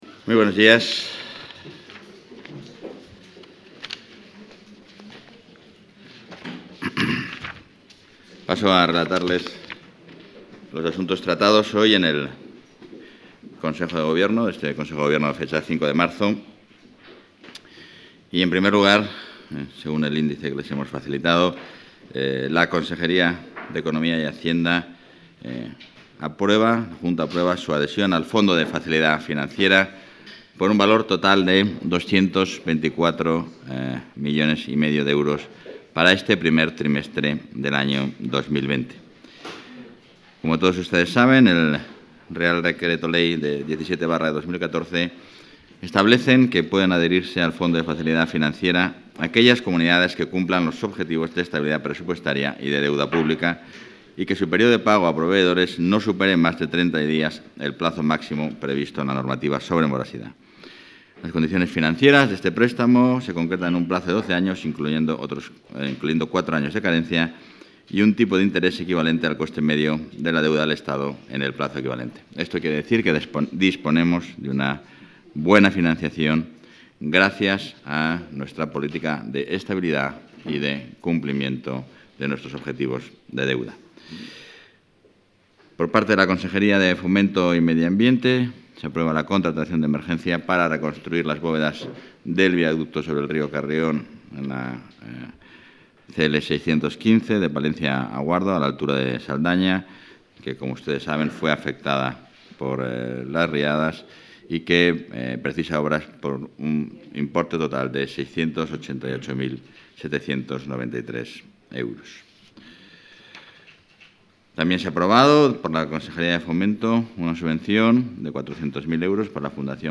Rueda de prensa.